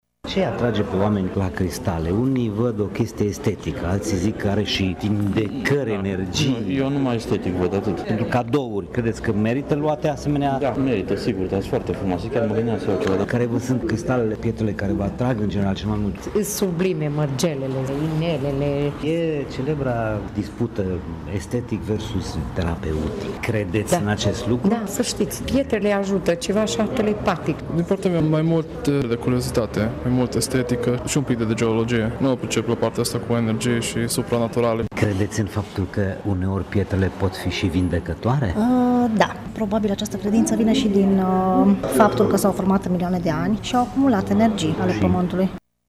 Mineralia-vox.mp3